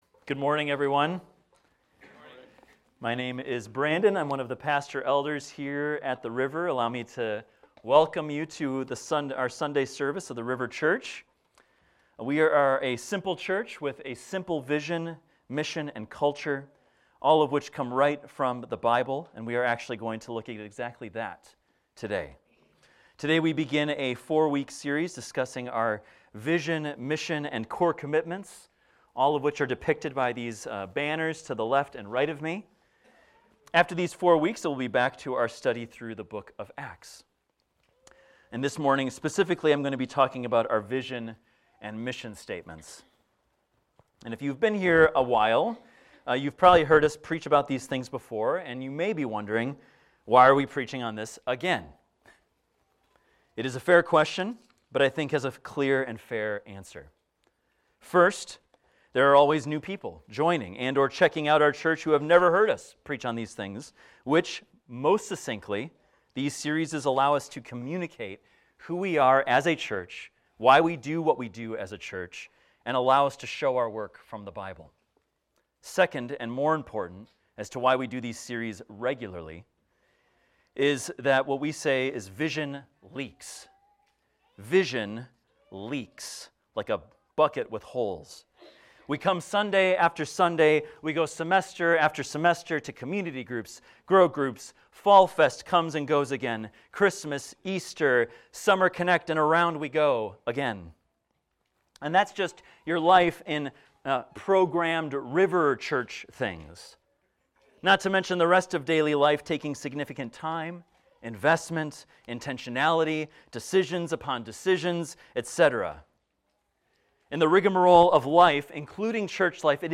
This is a recording of a sermon titled, "All In."